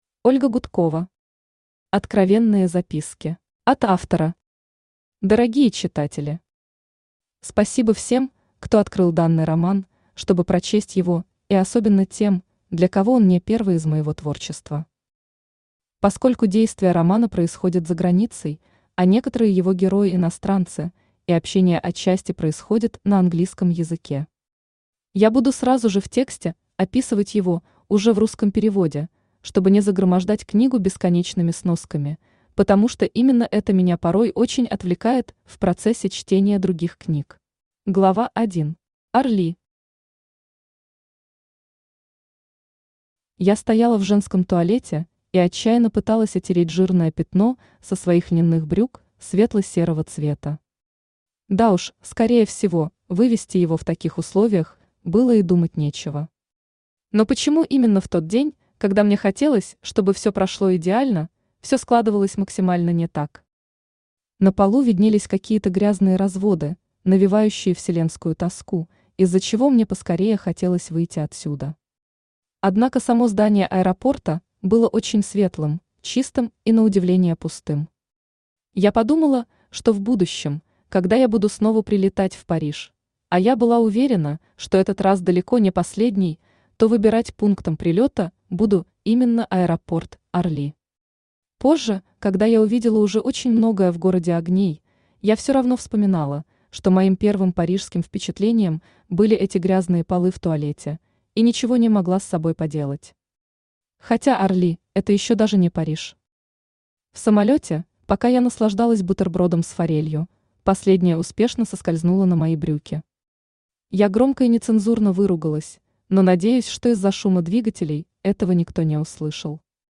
Аудиокнига Откровенные записки | Библиотека аудиокниг
Aудиокнига Откровенные записки Автор Ольга Гудкова Читает аудиокнигу Авточтец ЛитРес.